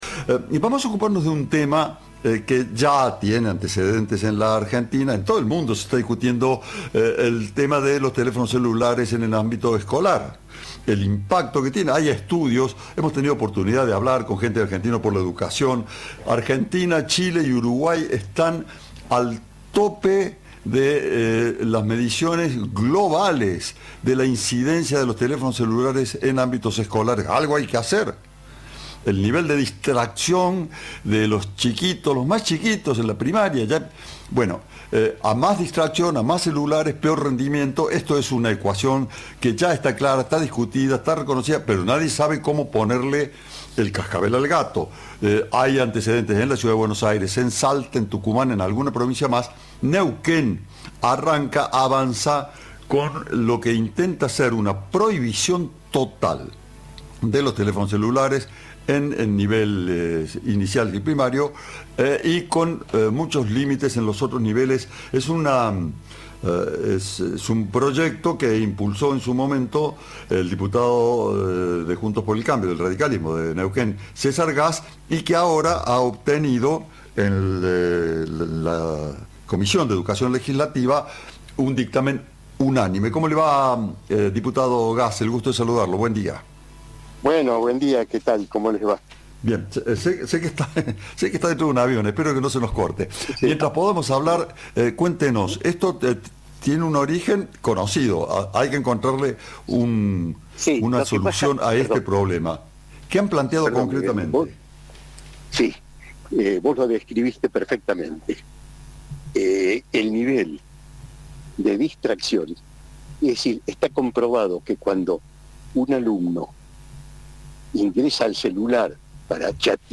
En diálogo con Cadena 3, el diputado Gass explicó los fundamentos del proyecto: "El nivel de distracción que generan los celulares en el ámbito escolar está comprobado.
Entrevista